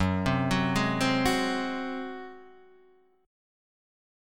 F#sus2b5 chord